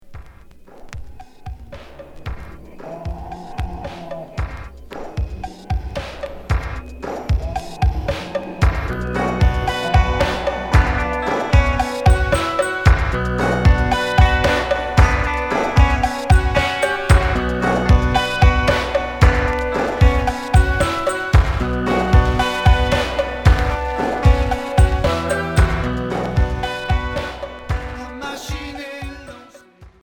New wave Unique 45t retour à l'accueil